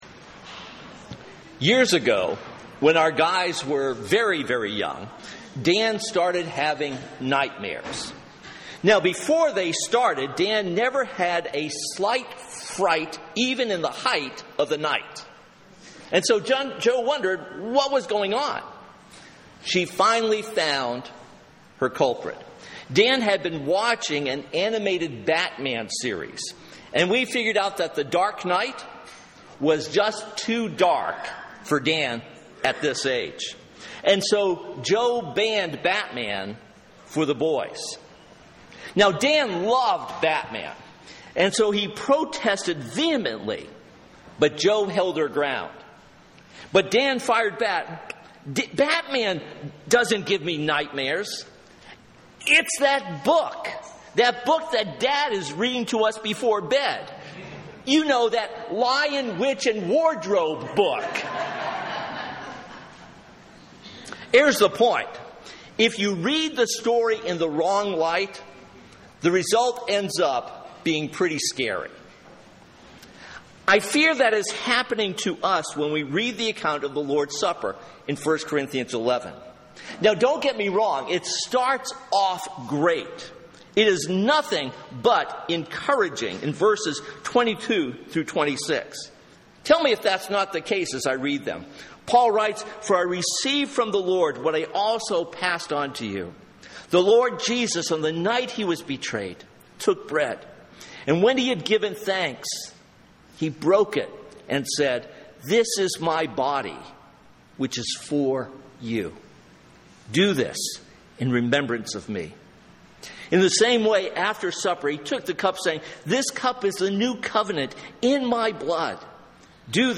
This sermon is based on 1 Corinthians 11:17-29.